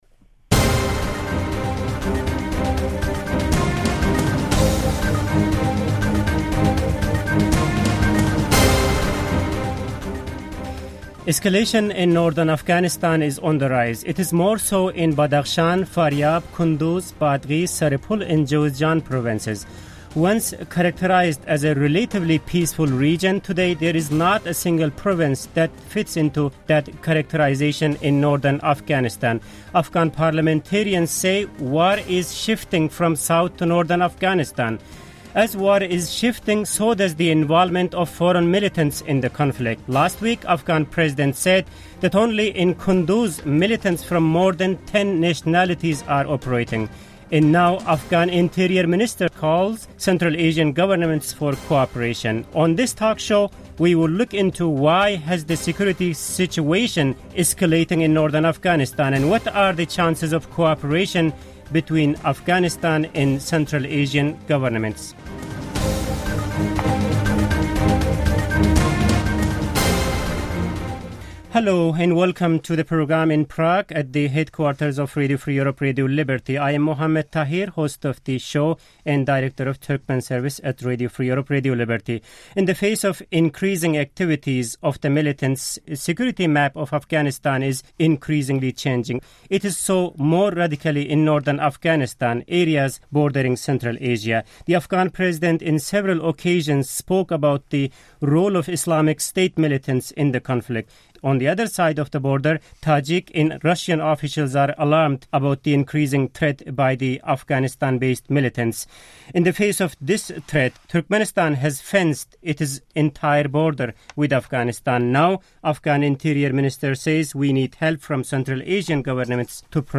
Roundtable: The Afghan Drawdown And Spillover In Central Asia
RFE/RL’s Turkmen Service panel discussion from July 8 on recent developments in northern Afghanistan and what Central Asia could do to help the Afghan government and military.